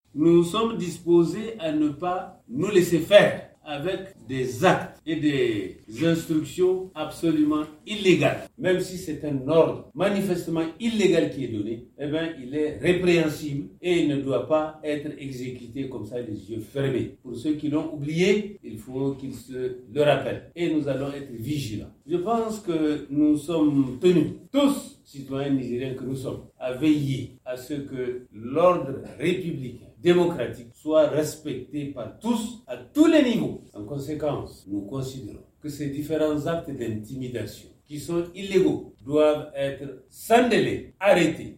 Interview de Mahamane Ousmane